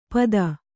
♪ pada